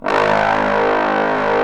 Index of /90_sSampleCDs/Roland LCDP06 Brass Sections/BRS_Bs.Trombones/BRS_Bs.Bone Sect